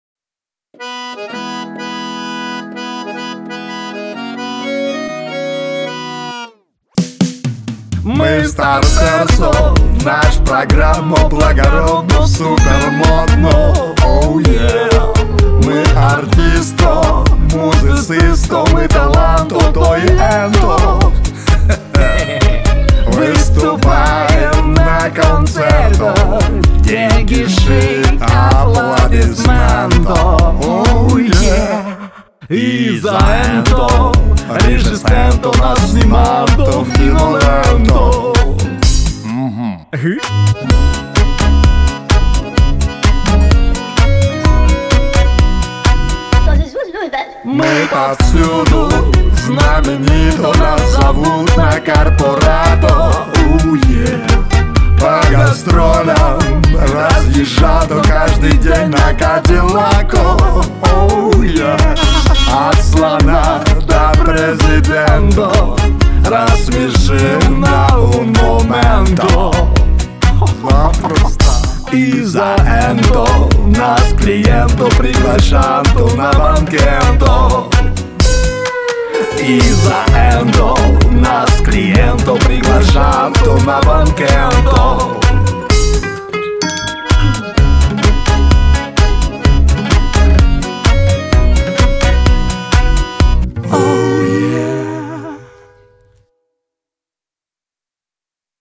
• Качество: 320, Stereo
поп
веселые
пародия